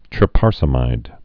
(trĭ-pärsə-mīd)